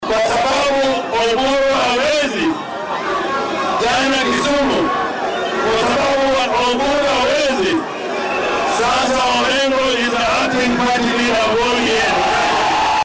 Guddoomiyaha ismaamulka Siaya James Orengo ayaa maanta shaaciyey inuu yahay sii hayaha xilka hoggaamiyaha xisbiga ODM. Isku soo bax siyaasadeed oo uu garabka Linda Mwananchi ku qabtay dowlad deegaanka Nakuru ayuu Orengo ka sheegay in Oburu Odinga uu jagadan ku fashilmay sida uu hadalka u dhigay sidaasi awgeedna uu isaga kala wareegay.